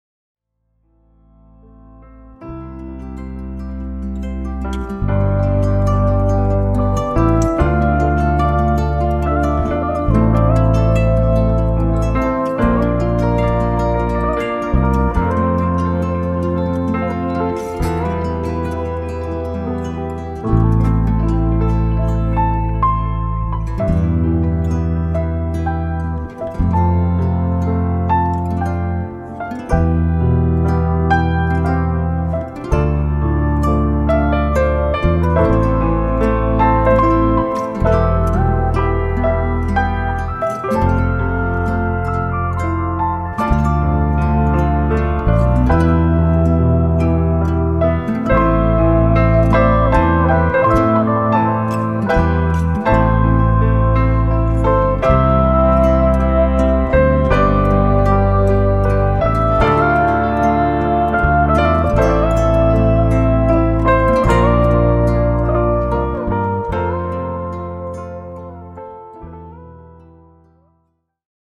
Our Romantic first date Love Theme with  special Nuances.